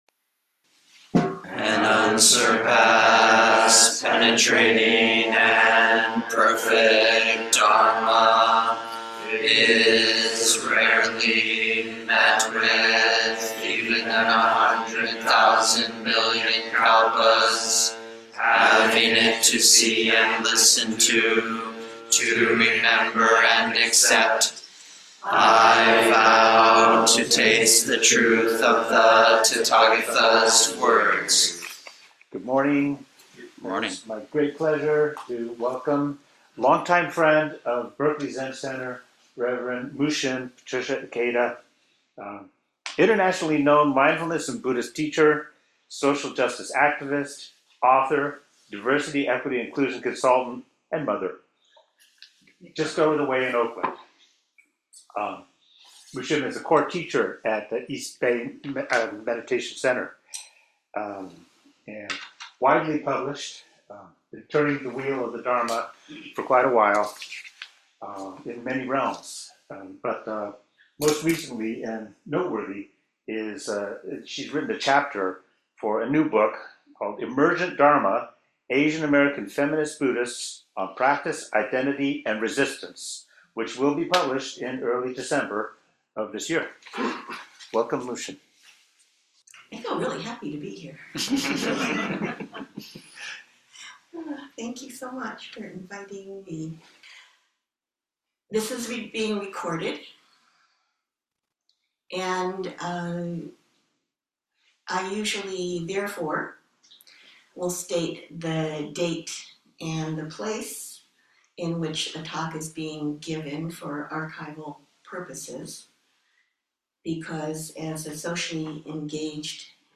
Lectures, talks, and classes on Zen Buddhism from Shogakuji Temple, Berkeley Zen Center, in Berkeley, California, USA.